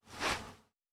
Quarterback Throw Normal.wav